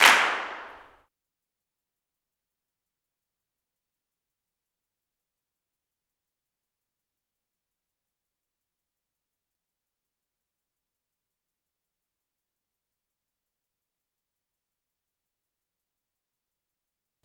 CLAP III.wav